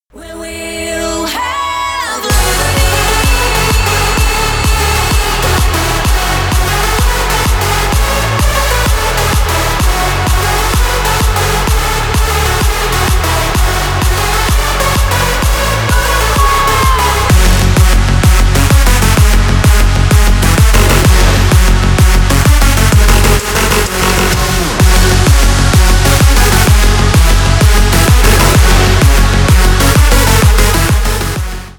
Электроника
клубные
громкие